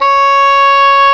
WND OBOE C#5.wav